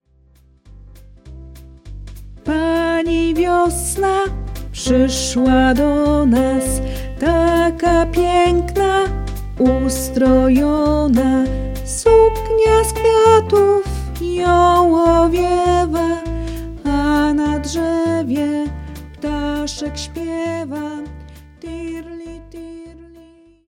• Pełną wersję piosenki z wokalem – plik MP3
Podgląd piosenki: